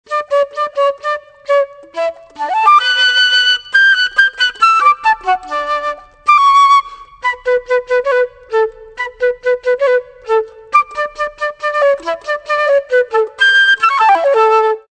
FLUTSOLO.mp3